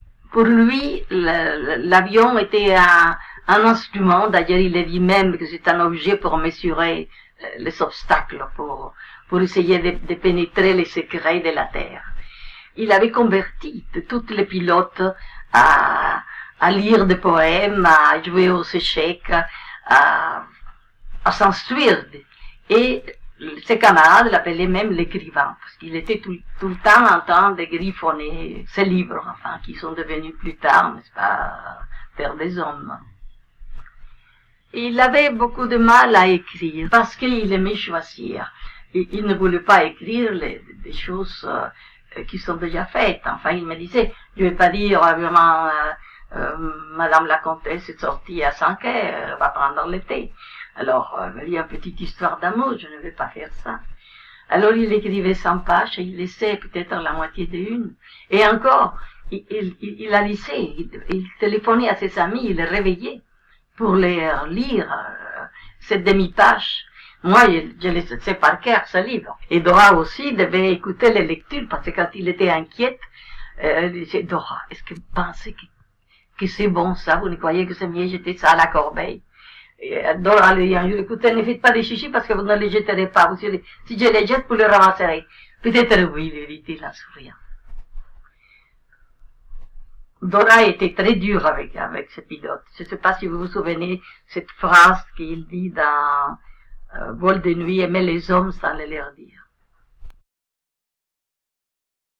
Ecouter Consuelo de Saint
04-Lavion-est-un-instrument-extrait.mp3